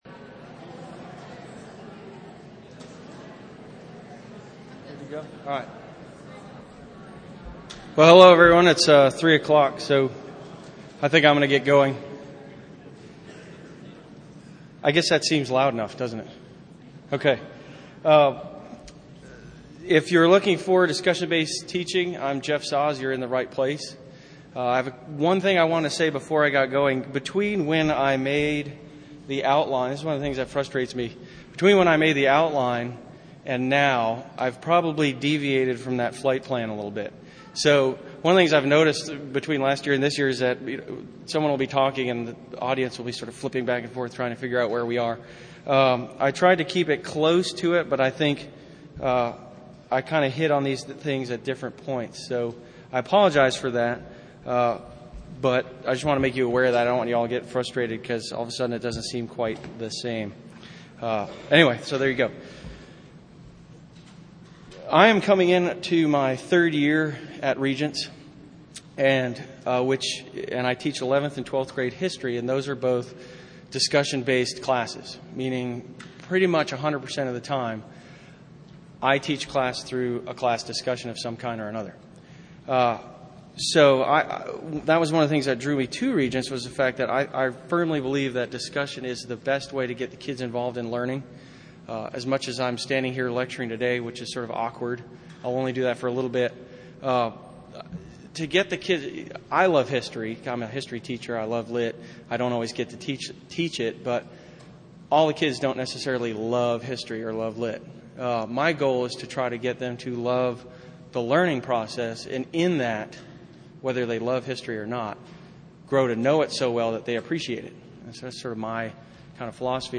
2008 Workshop Talk | 1:05:59 | All Grade Levels, General Classroom
Speaker Additional Materials The Association of Classical & Christian Schools presents Repairing the Ruins, the ACCS annual conference, copyright ACCS.